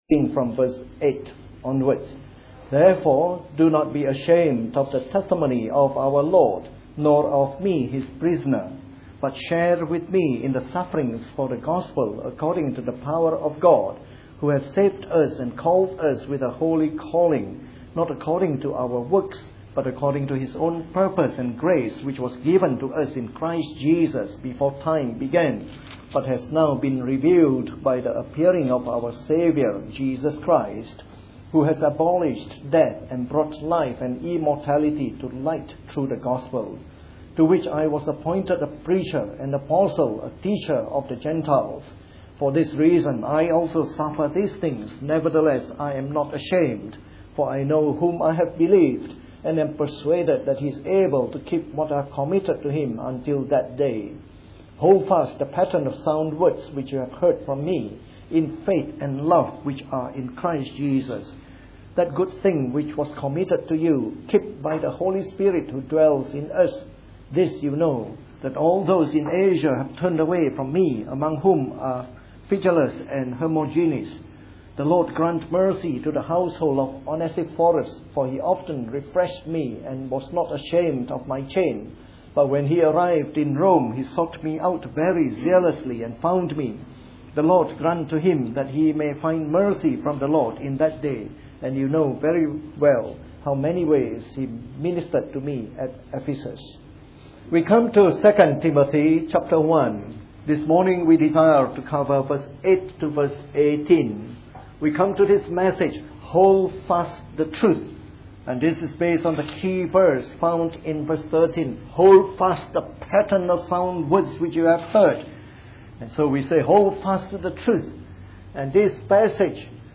A sermon in the morning service from our series on 2 Timothy.